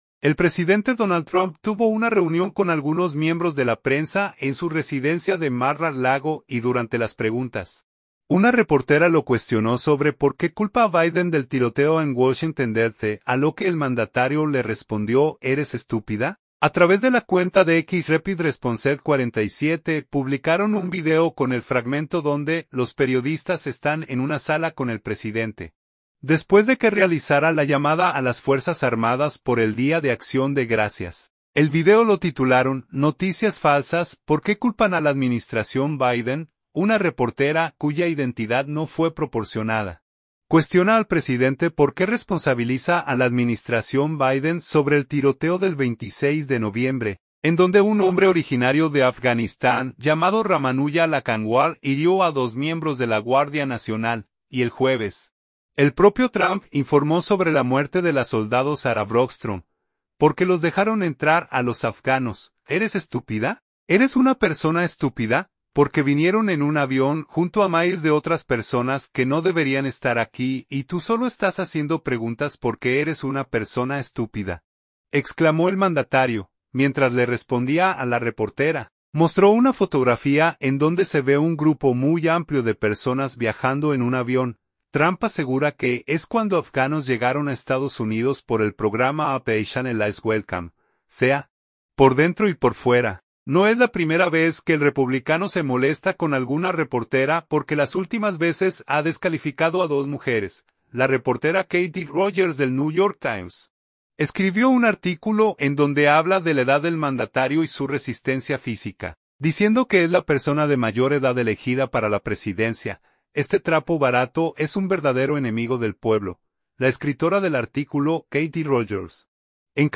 El presidente Donald Trump tuvo una reunión con algunos miembros de la prensa en su residencia de Mar-a-Lago, y durante las preguntas, una reportera lo cuestionó sobre por qué culpa a Biden del tiroteo en Washington D.C., a lo que el mandatario le respondió: “¿Eres estúpida?”.
A través de la cuenta de X Rapid Responsed 47, publicaron un video con el fragmento donde, los periodistas están en una sala con el presidente, después de que realizara la llamada a las fuerzas armadas por el Día de Acción de Gracias.